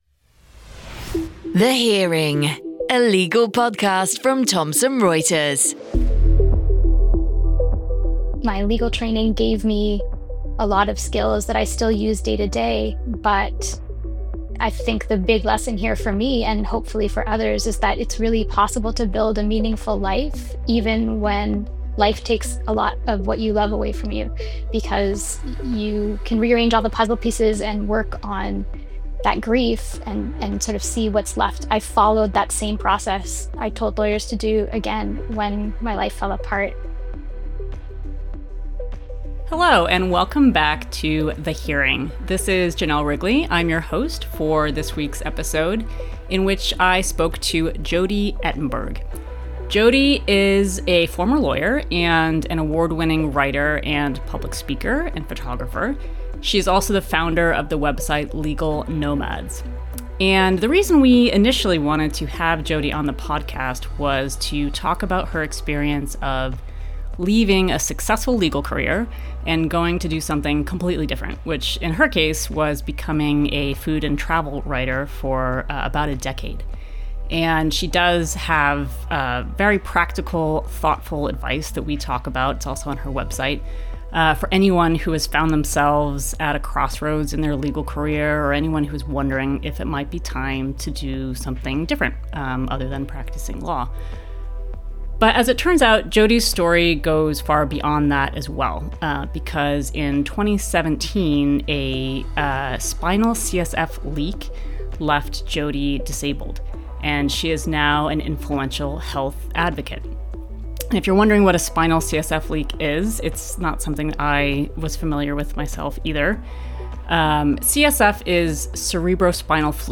This episode features a conversation